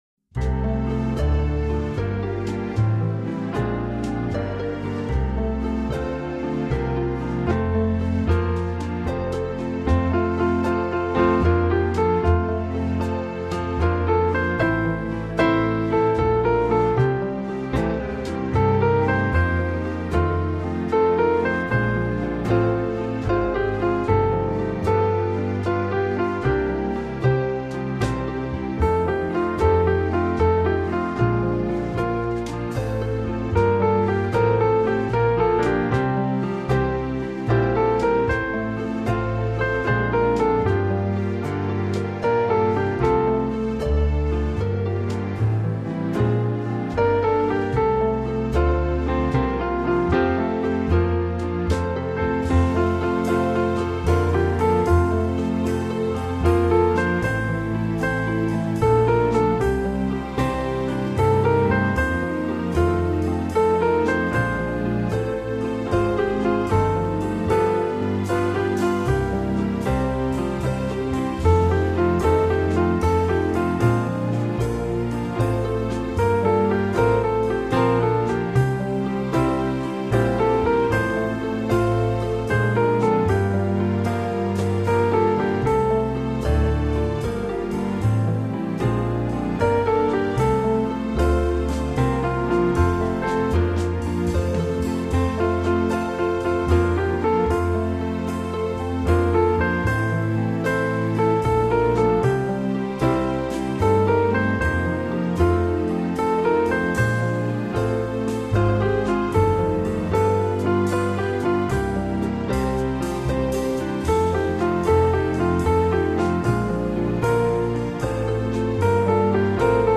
rather interesting 9/8 oddity
It’s going to take a major effort to keep this light: